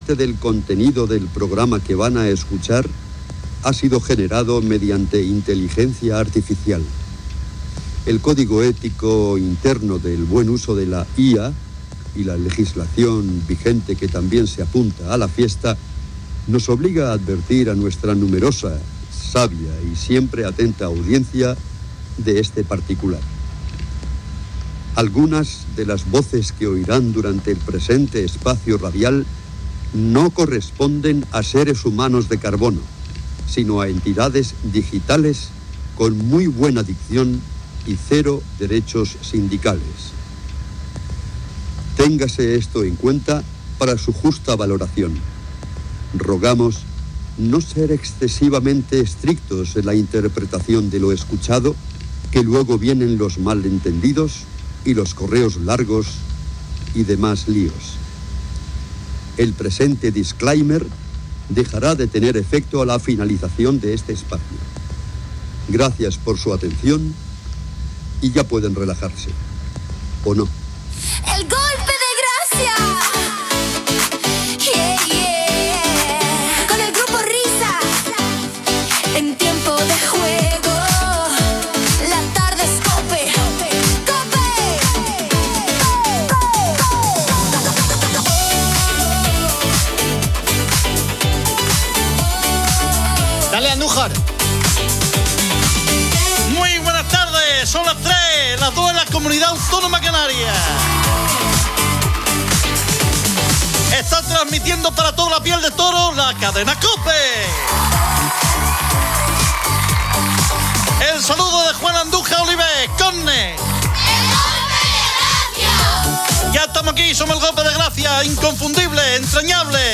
El programa emplea voces generadas por inteligencia artificial y advierte de su uso.